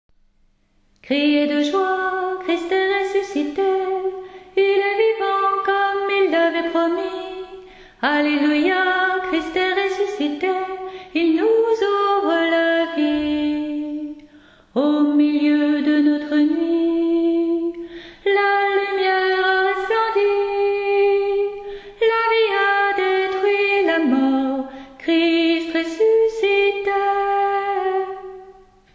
Voix chantée (MP3)COUPLET/REFRAIN
ALTO